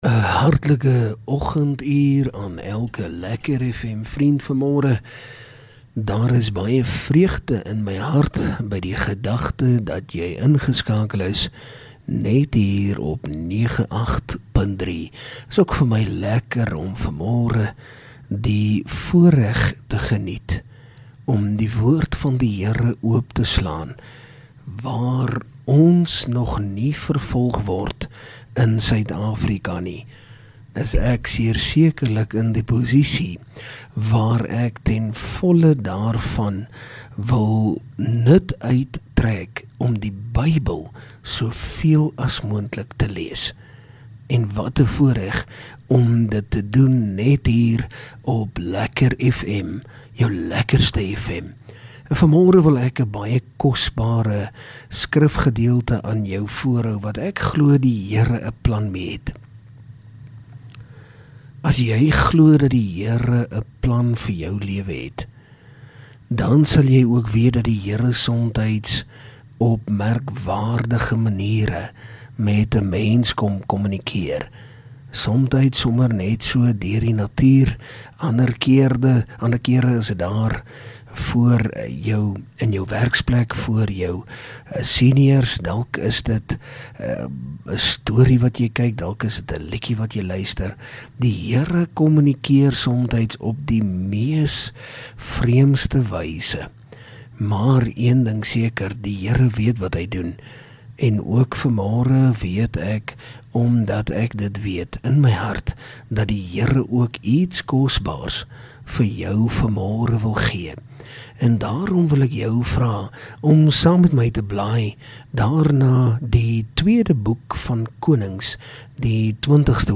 LEKKER FM | Oggendoordenkings